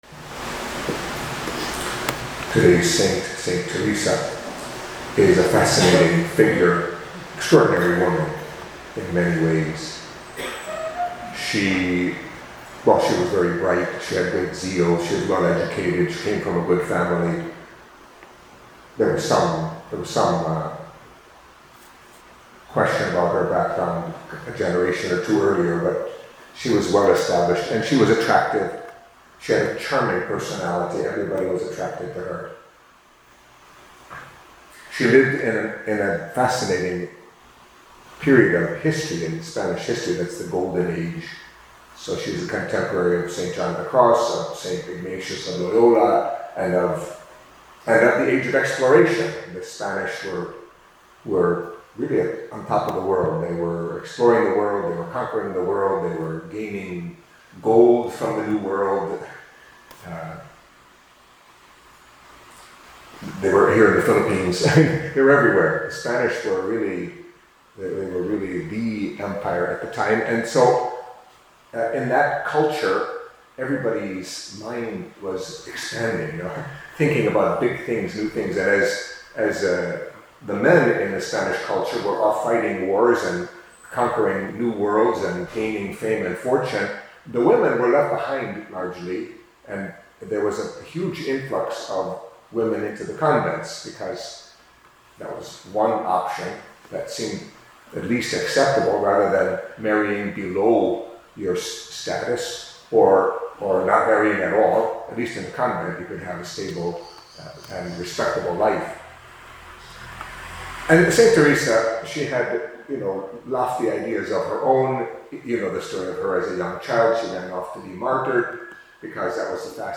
Catholic Mass homily for Wednesday of the Twenty-Eighth Week in Ordinary Time